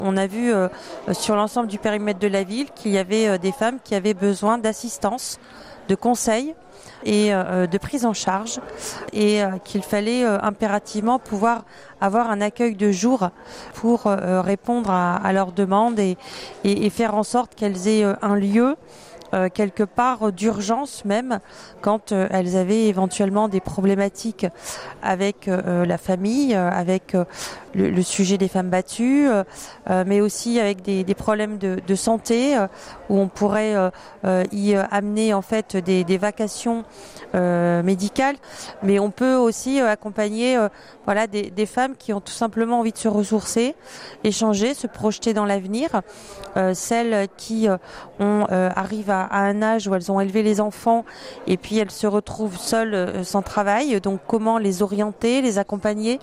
Natacha Bouchart, la maire de Calais.